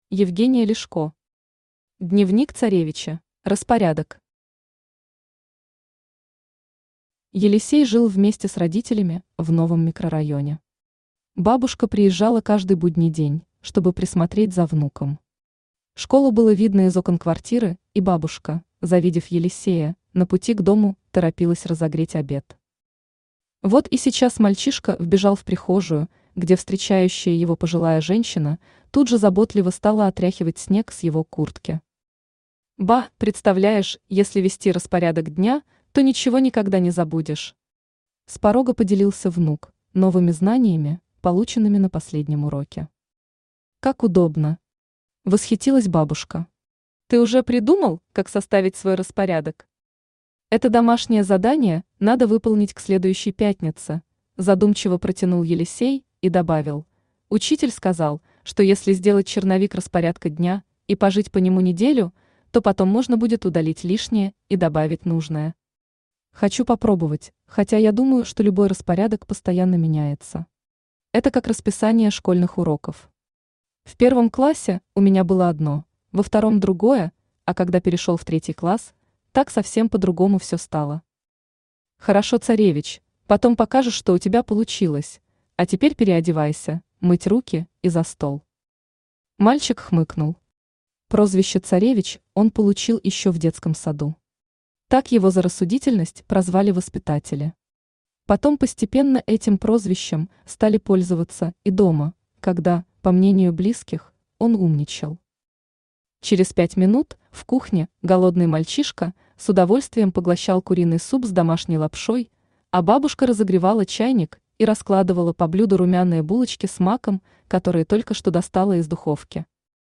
Аудиокнига Дневник Царевича | Библиотека аудиокниг
Aудиокнига Дневник Царевича Автор Евгения Ляшко Читает аудиокнигу Авточтец ЛитРес.